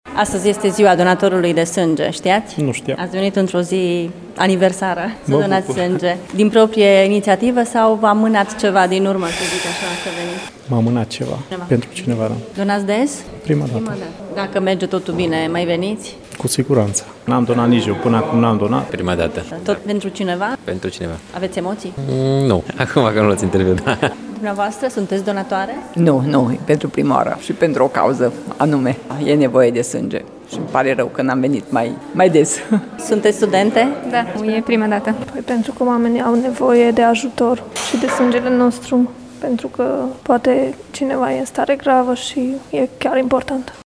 O bună parte dintre târgumureșenii care s-au prezentat astăzi la Centrul de donare de sânge erau la prima încercare de acest fel: